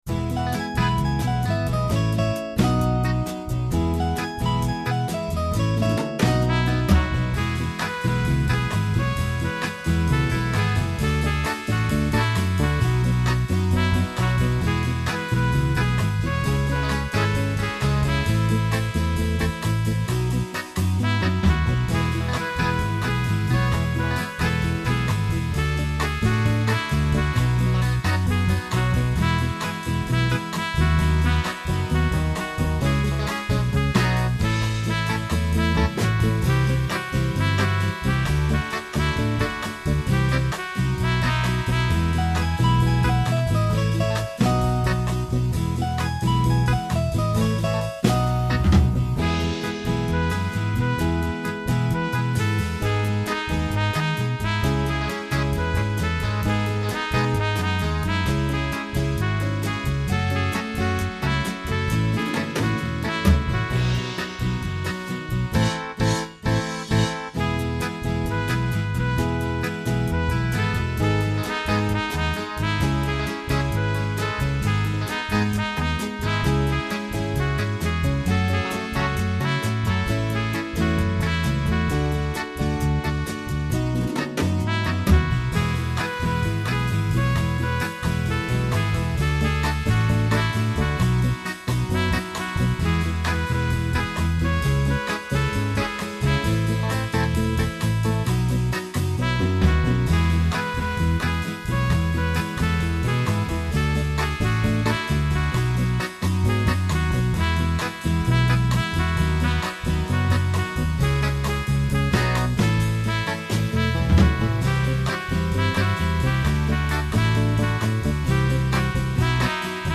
OK this is a gathering song.
My backing goes more latin pop.